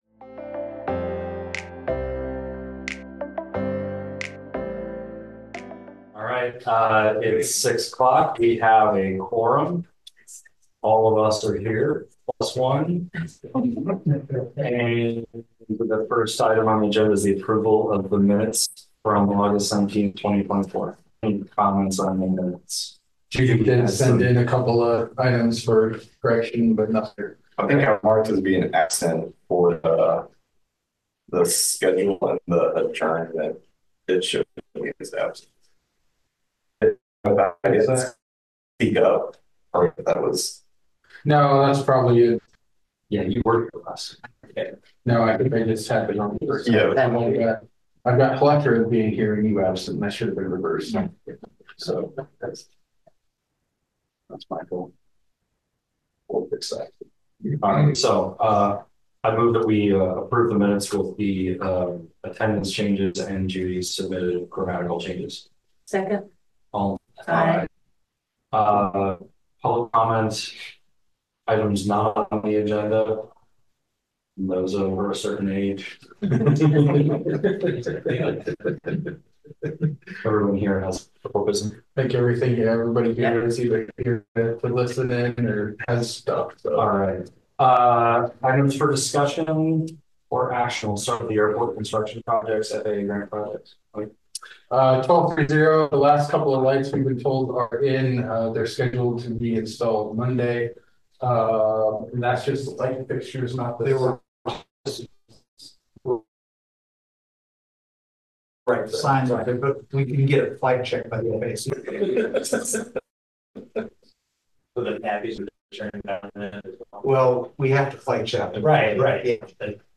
Regular monthly meeting of the City of Iowa City's Airport Commission.